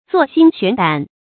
坐薪懸膽 注音： ㄗㄨㄛˋ ㄒㄧㄣ ㄒㄨㄢˊ ㄉㄢˇ 讀音讀法： 意思解釋： 坐臥在柴草上，懸膽嘗其味。比喻刻苦自勵，奮發圖強。